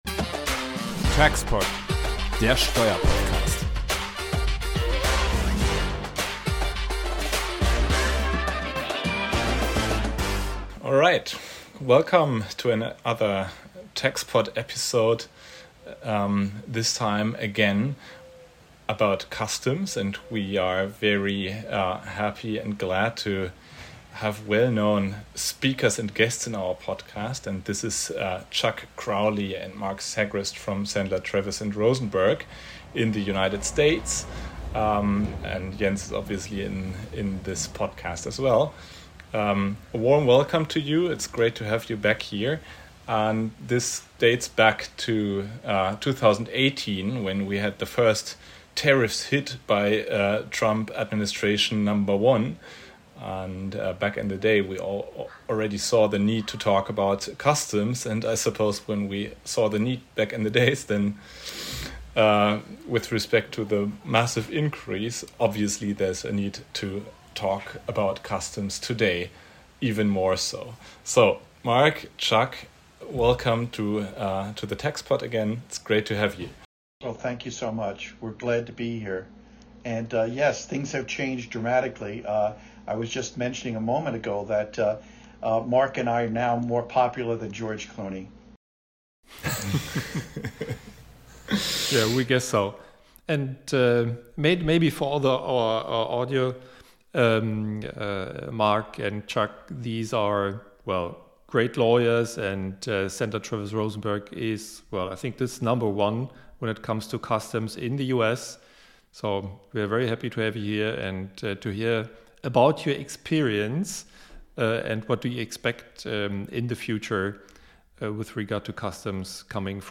Die Diskussion ist „on a moving target”.